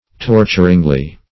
torturingly - definition of torturingly - synonyms, pronunciation, spelling from Free Dictionary Search Result for " torturingly" : The Collaborative International Dictionary of English v.0.48: Torturingly \Tor"tur*ing*ly\, adv. So as to torture.